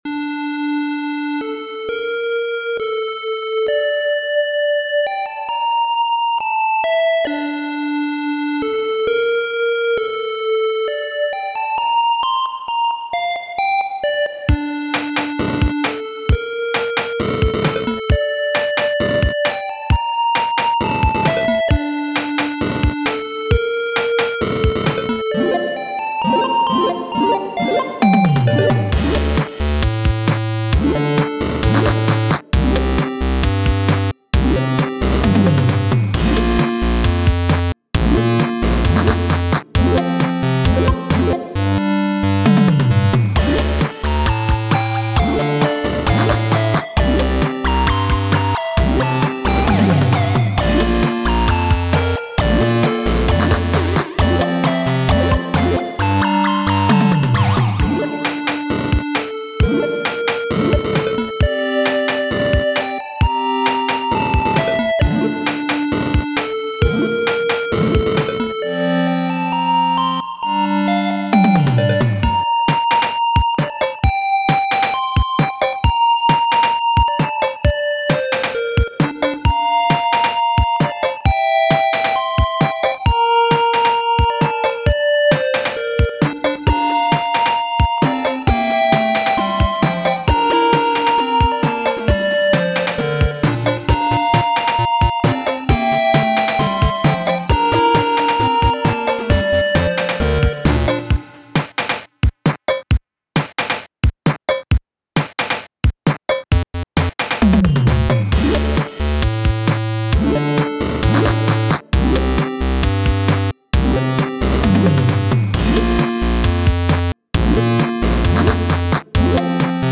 8-bit dungeon_synth fantasy